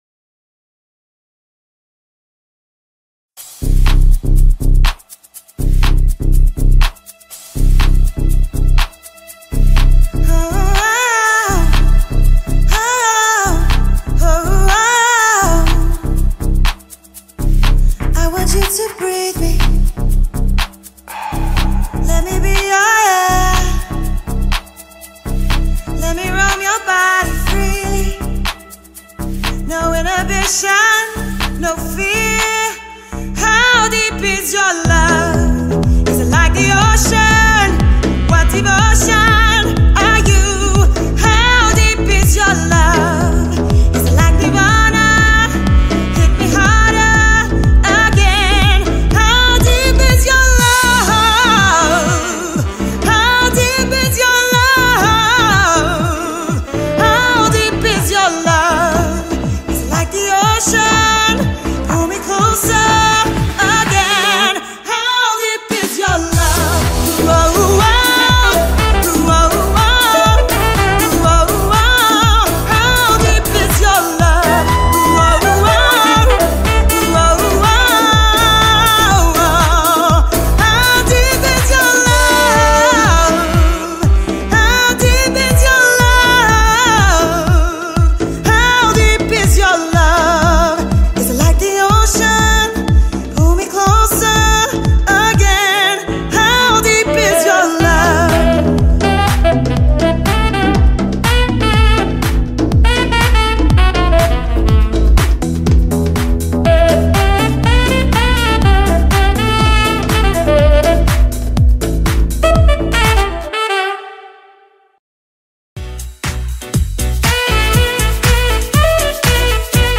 Vocals | DJ | Percussion | Sax | MC
four-piece DJ band
combined with a saxophonist, percussionist and DJ
Showreel